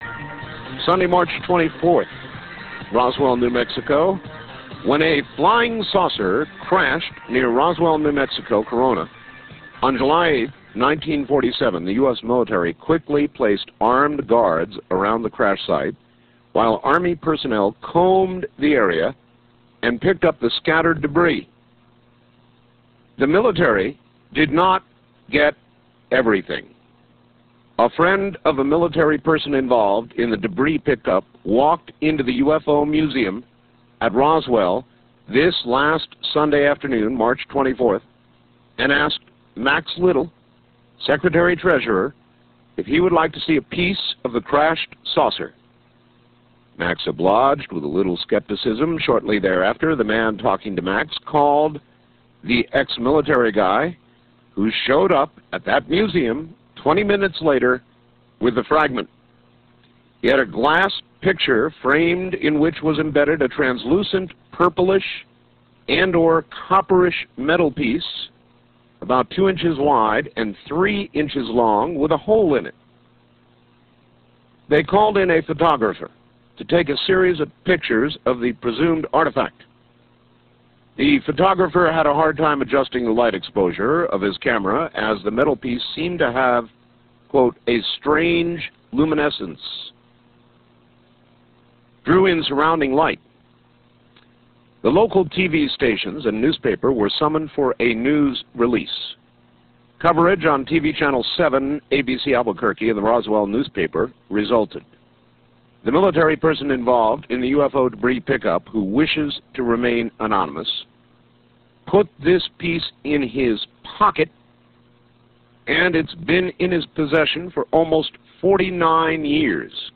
Crash wreckage metal photo from the Roswell Museum. Art reads newspaper article regarding this object.